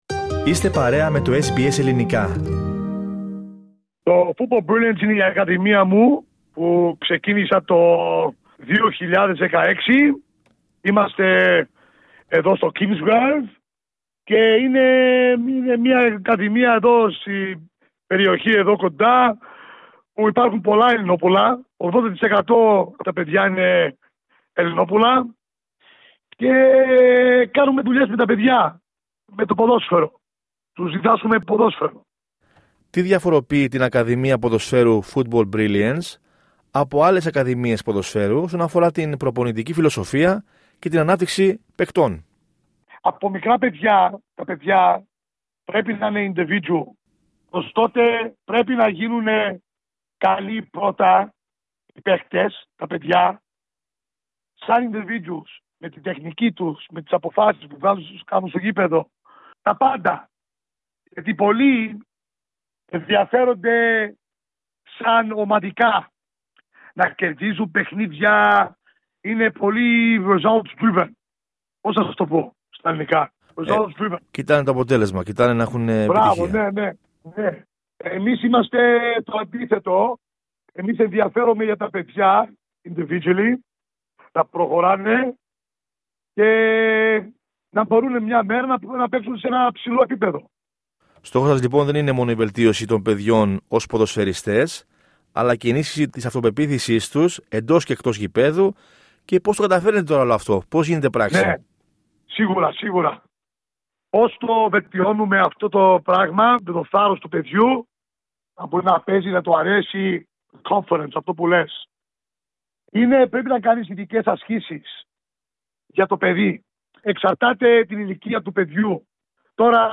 Οι πρωταγωνιστές του έπους του 2004 μιλούν στο SBS